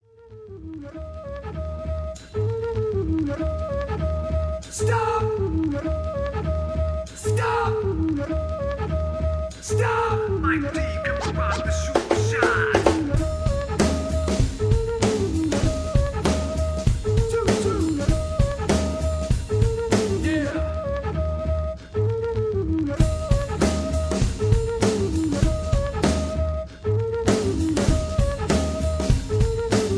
karaoke, mp3 backing tracks
rock, r and b, rap, rock and roll